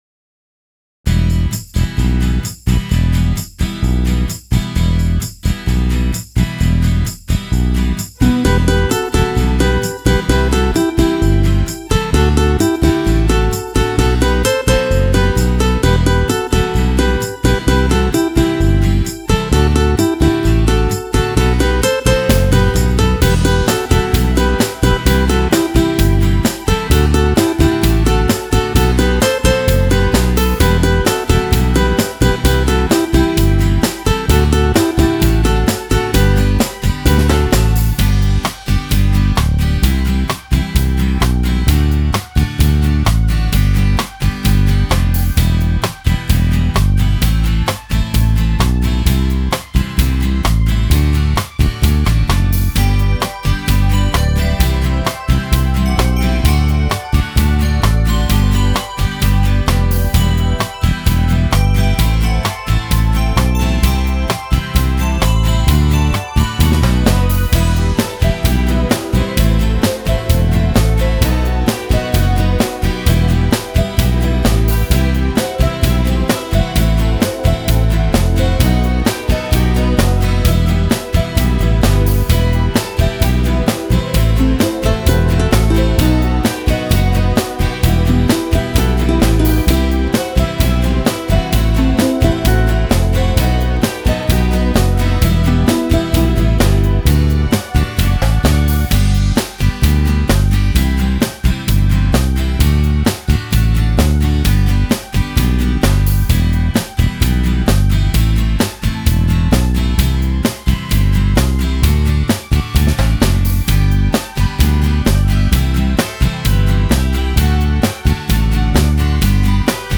Слушать или скачать минус к песне